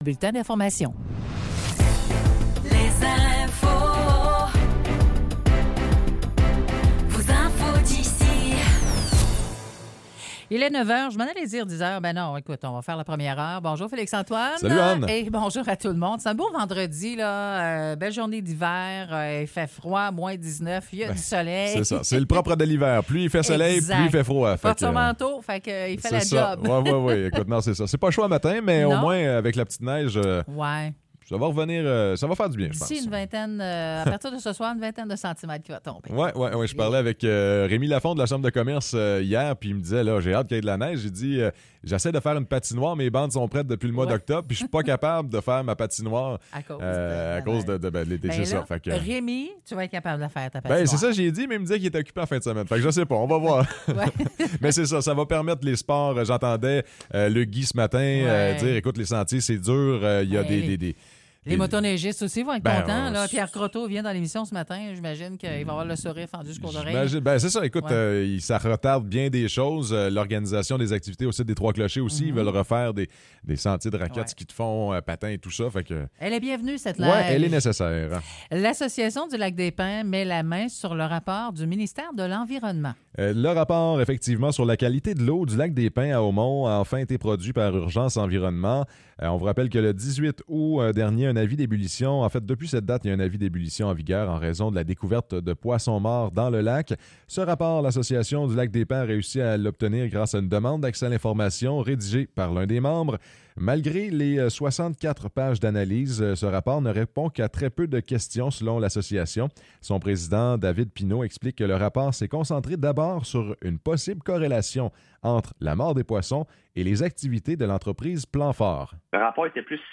Nouvelles locales - 12 janvier 2024 - 9 h